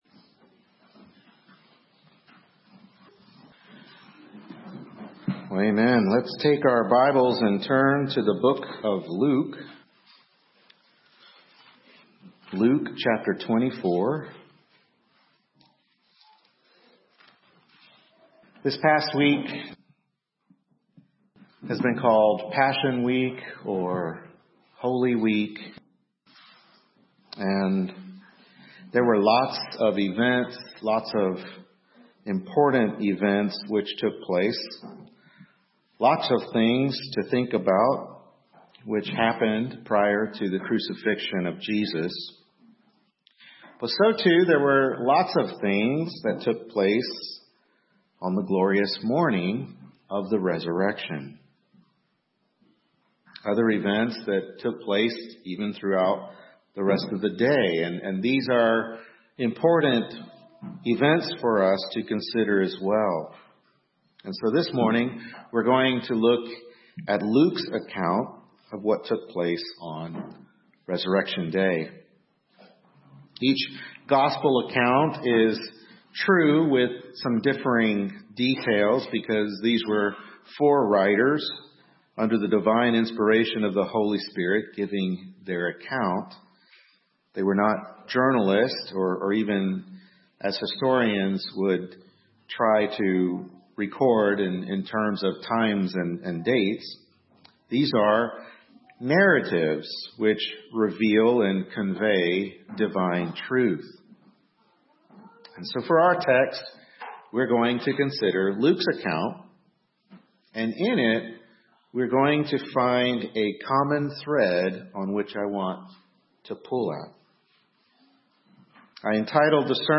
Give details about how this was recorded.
Luke 24 Service Type: Morning Worship Service Luke 24 Was it Necessary for the Messiah to Suffer and Rise on the Third Day?